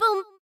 rahRahSisBoomBaBoomS4.ogg